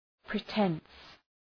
pretence.mp3